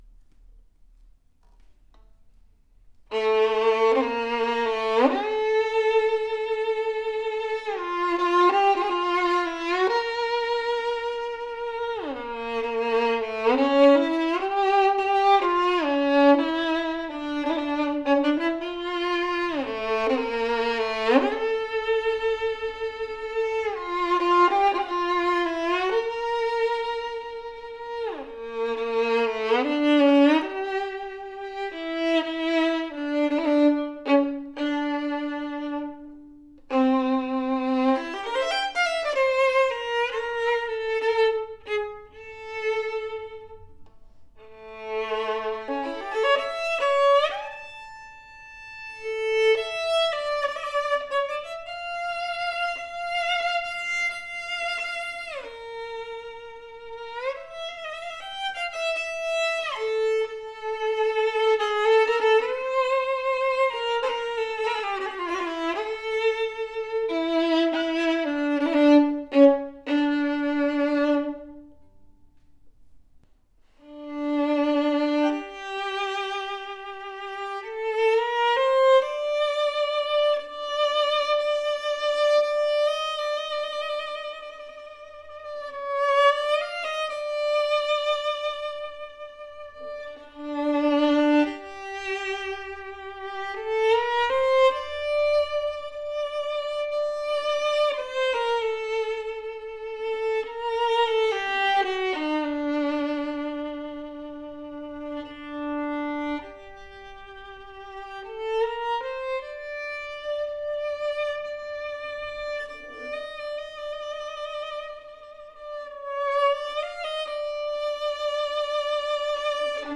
• AAA seasoned European spruce, master plate tuned for best resonance and projection.
POWERFULL, dark, projective tone with fantastic projection that carries the tone on distance!
AAA graded seasoned wood that’s plate tuned and graduated for an inviting, antique voice with warm and full projection. Deep ringing G string, great depth and sings with bold dimension. Sweet and focused mid register that speaks with clarity, clean E string with a singing tone quality.